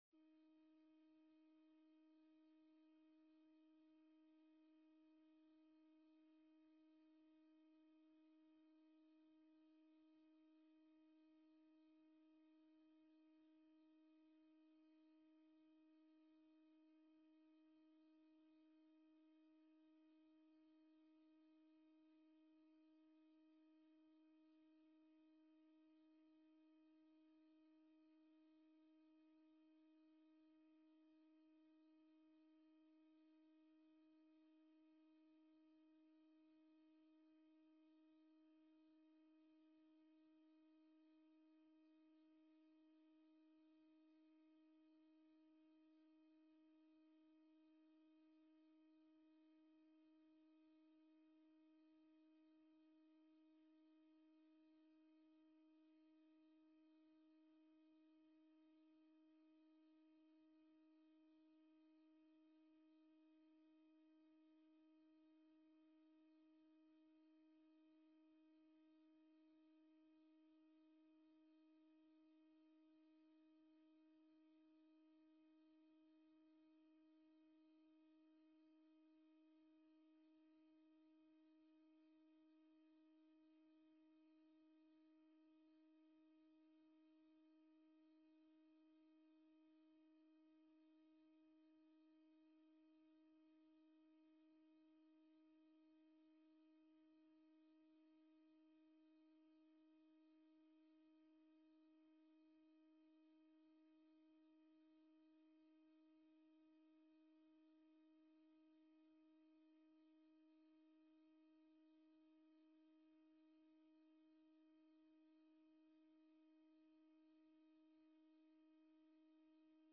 De vergadering wordt digitaal gehouden gezien de aangescherpte maatregelen.